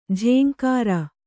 ♪ jhēŋkāra